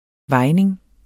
Udtale [ ˈvɑjneŋ ]